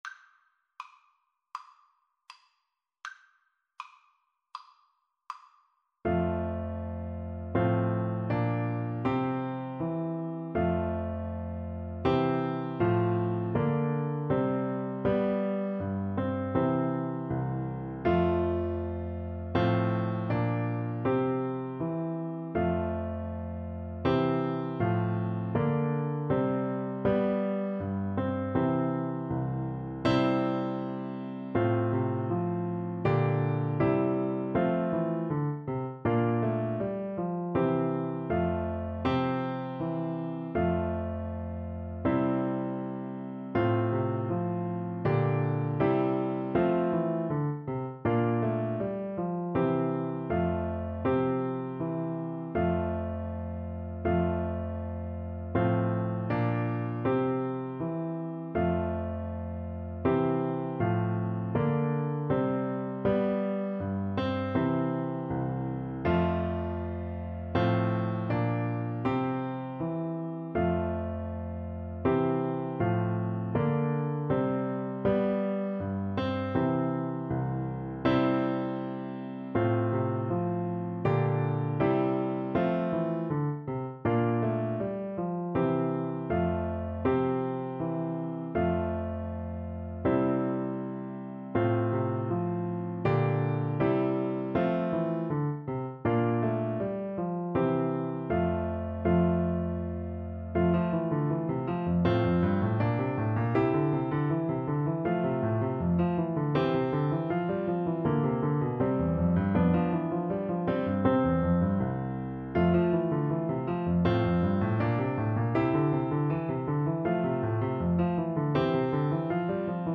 F major (Sounding Pitch) (View more F major Music for Bassoon )
Allegretto =80
Classical (View more Classical Bassoon Music)